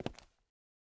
Illusion-UE5/Concrete Walk - 0001 - Audio - Stone 01.ogg at dafcf19ad4b296ecfc69cef996ed3dcee55cd68c